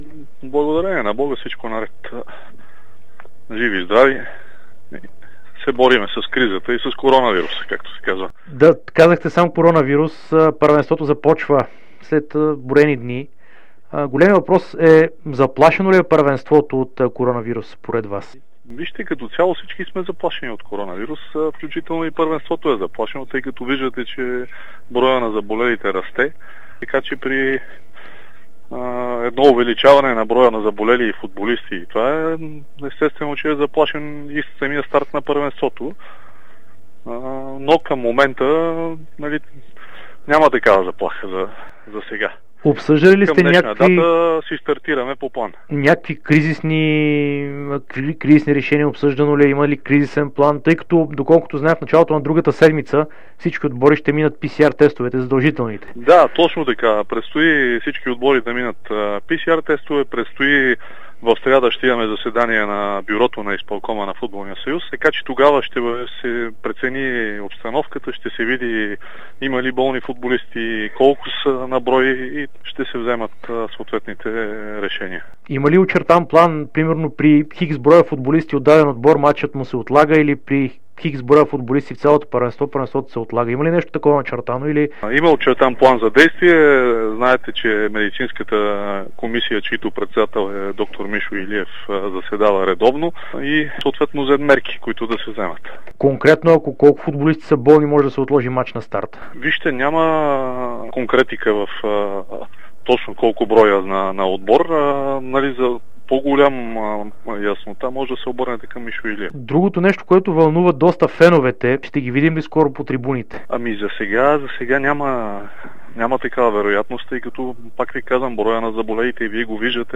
обширно интервю пред dsport и Дарик радио